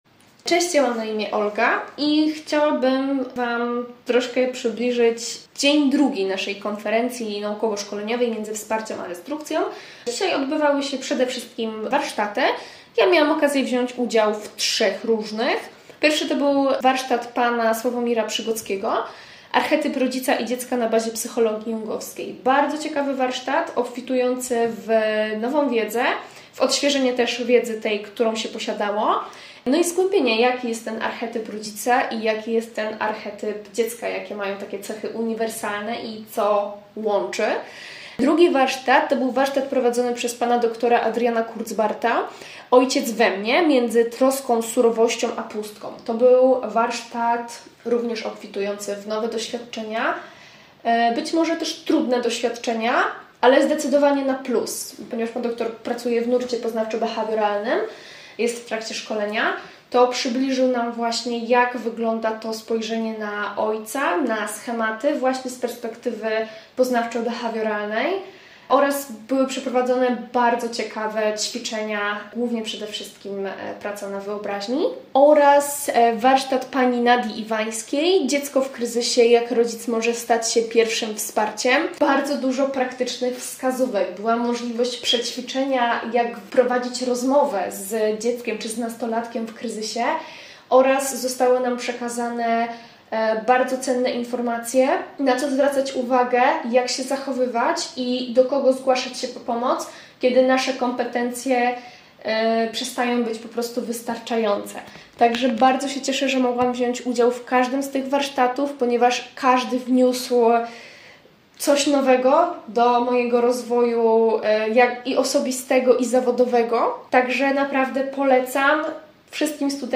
członkini komitetu organizacyjnego konferencji